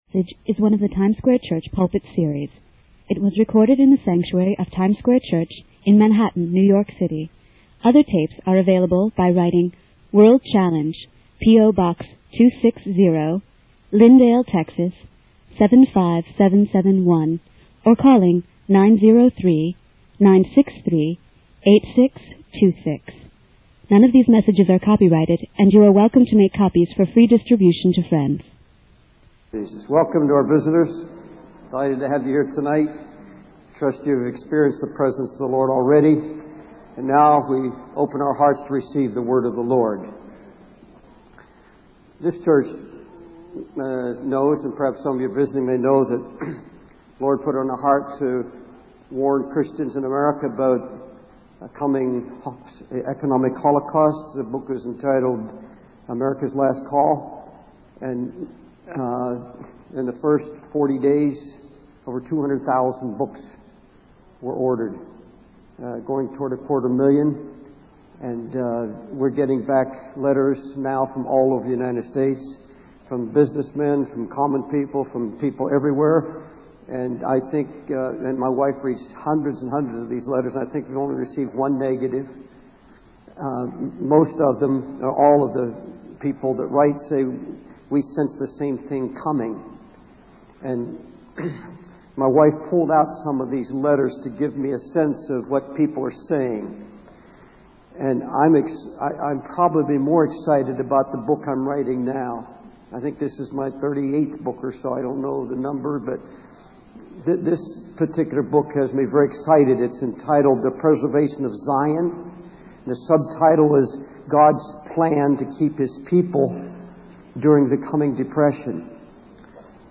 In this sermon, the preacher emphasizes the importance of being convicted by the Holy Ghost and acknowledging one's sins before God.